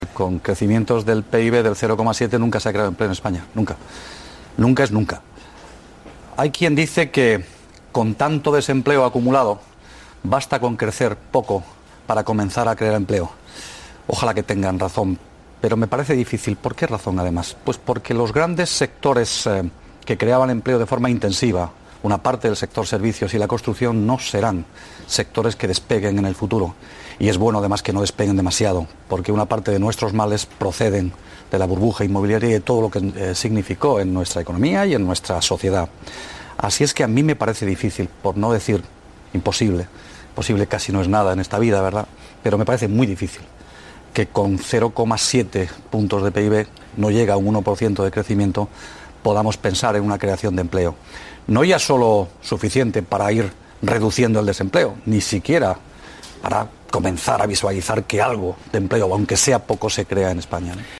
Fragmento de la entrevista ed Valeriano Gómez en Los desayunos de TVE un día después de hacerse públicos los datos de desempleo de septiembre 3/10/2013